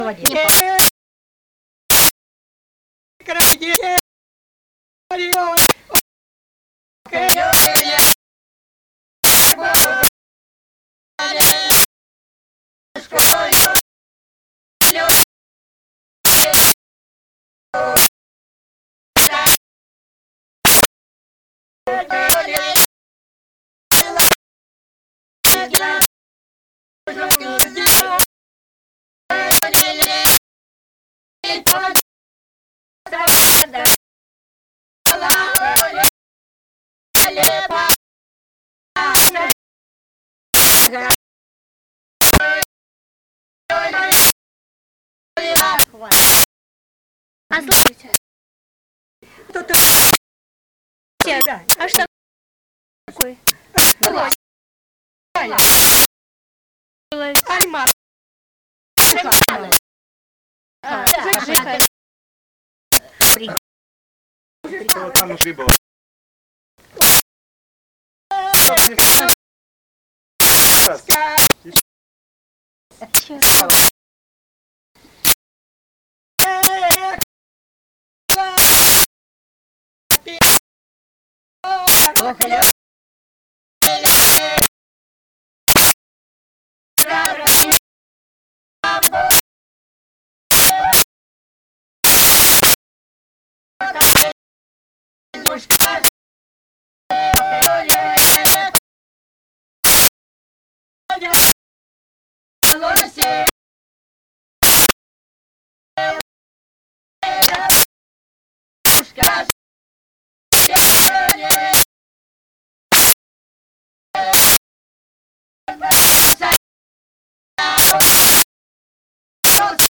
Музыкальная стилистика свадебных песен села Безгодовка типична для южнорусской традиции.
Характерным для местной традиции является использование исполнительского приёма узвизгивания — октавного флажолета, тянущегося одну-две счётные единицы на звук «у».
В целом местные свадебные песни отличаются лирическим окрасом, который проявляется в более спокойном темпе исполнения, плавном голосоведении с терцовой надстройкой верхнего голоса.
01 Свадебная песня «Ишли-прошли красные девки гурьбою» в исполнении жительниц с. Безгодовка Валуйского р-на Белгородской обл.